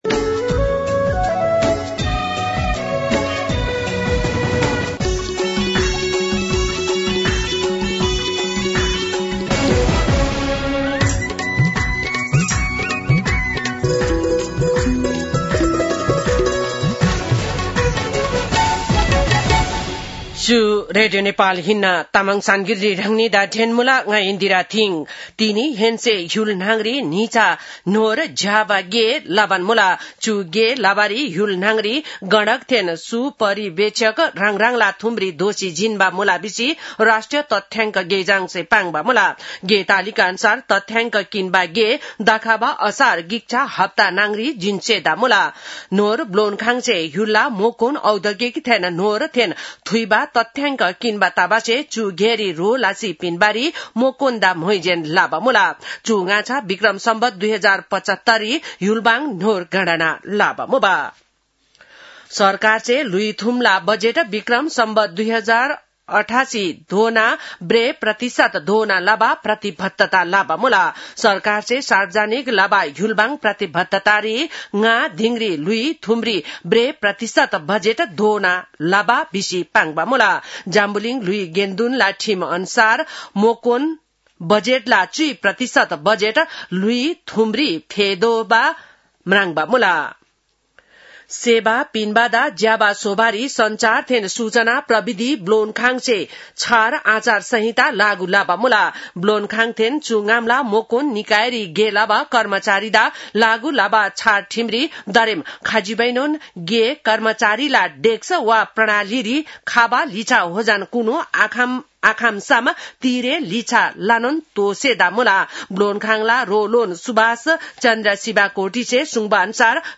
तामाङ भाषाको समाचार : २ वैशाख , २०८३